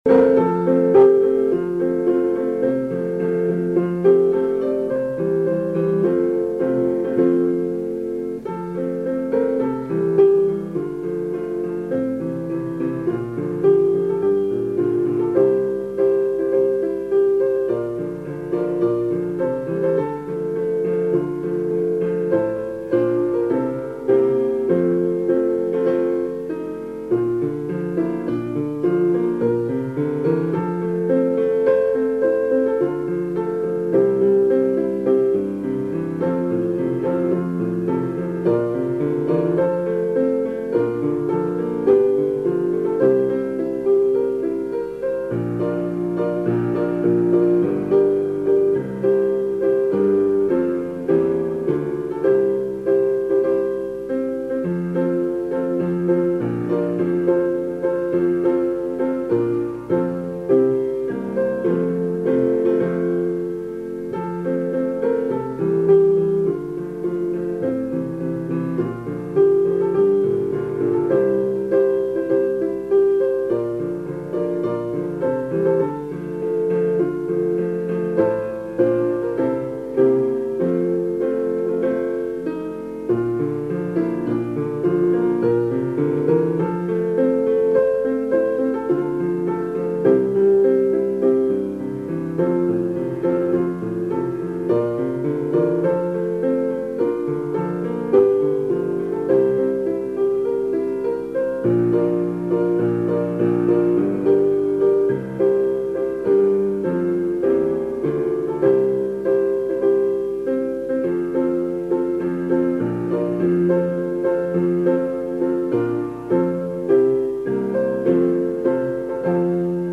☆彡　生出小学校　校歌伴奏